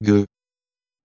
LA LETTERA -U La lettera u è generalmente muta nei gruppi -gua, -gue, -gui, e quando segue la q. eccezioni: in piqûre (puntura) la -u suona.
gueux.mp3